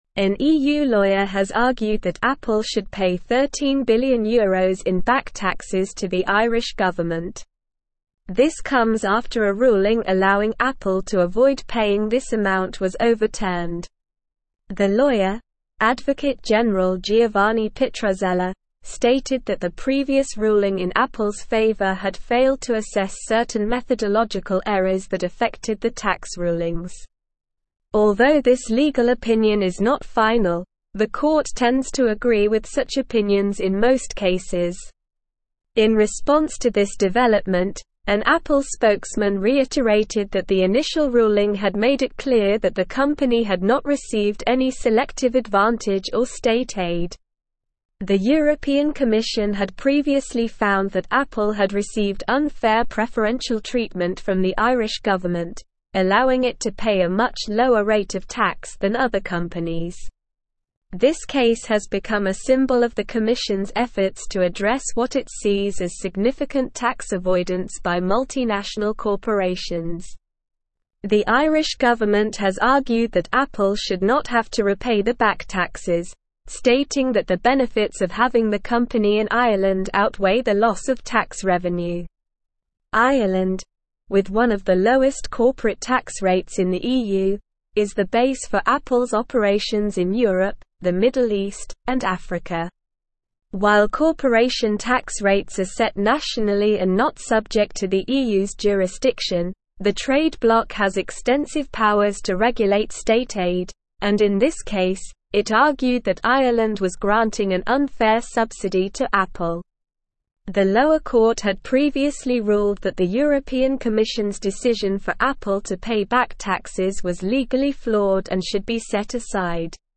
Slow
English-Newsroom-Advanced-SLOW-Reading-EU-Adviser-Recommends-Overturning-Ruling-on-Apples-Taxes.mp3